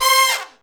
C5 POP FALL.wav